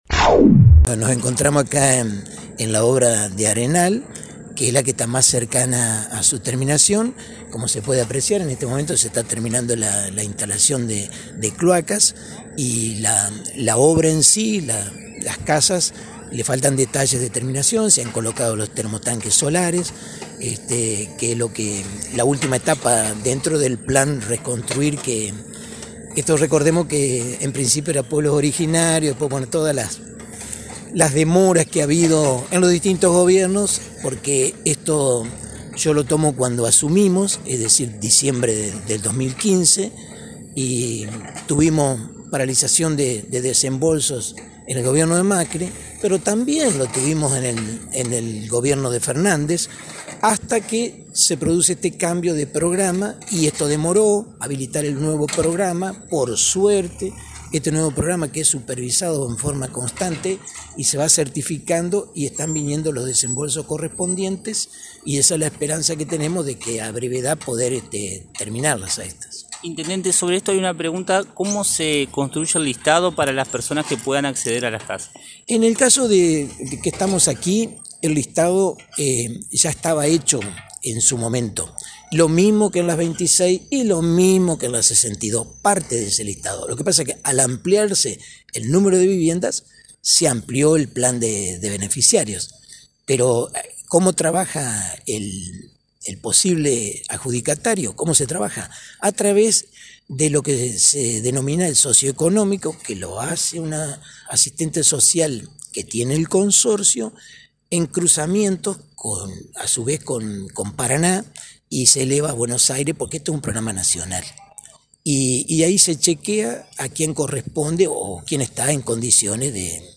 En diálogo con este medio, el intendente Domingo Maiocco, comentó sobre el avance en la obra y sobre el proceso de adjudicación mediante el cual 16 familias podrán acceder a la casa propia.